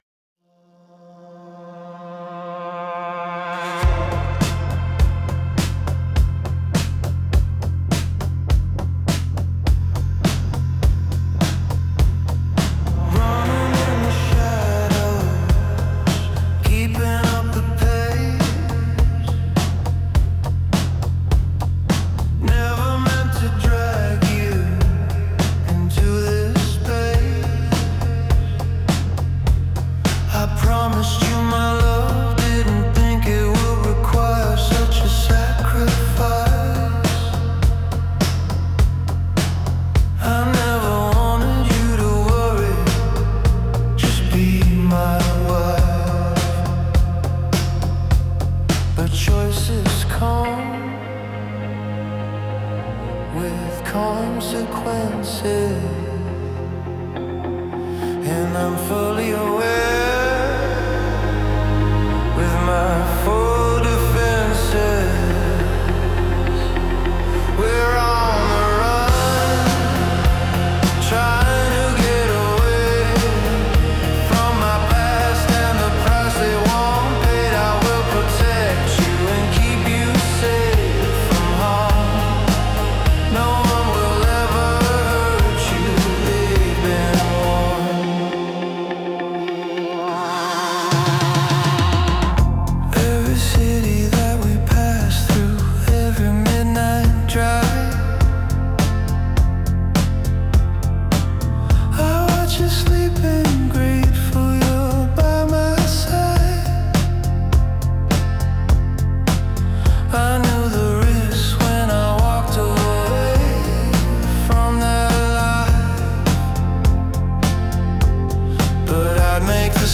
Indie Rock • Tension • Momentum • Pursuit